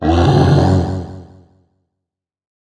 bear_spawn_01.wav